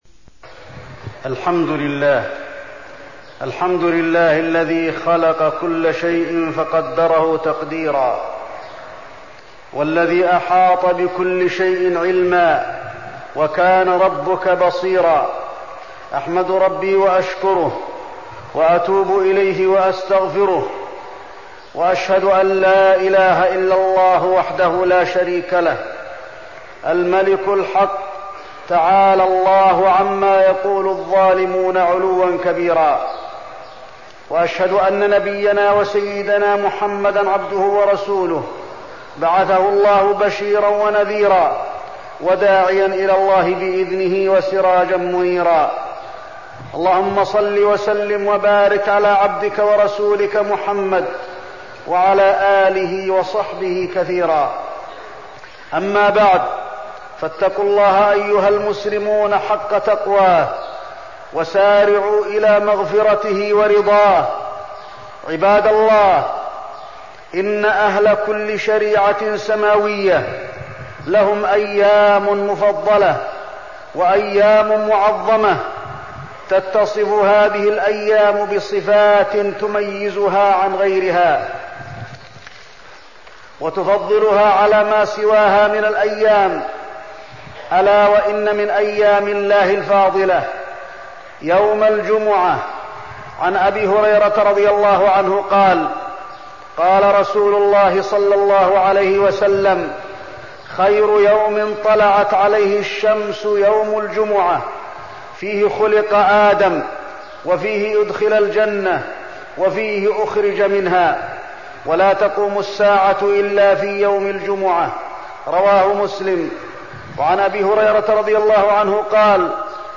تاريخ النشر ٣٠ شوال ١٤١٥ هـ المكان: المسجد النبوي الشيخ: فضيلة الشيخ د. علي بن عبدالرحمن الحذيفي فضيلة الشيخ د. علي بن عبدالرحمن الحذيفي فضل يوم الجمعة